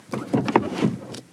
Palanca de cambios de un coche Golf 1
Sonidos: Transportes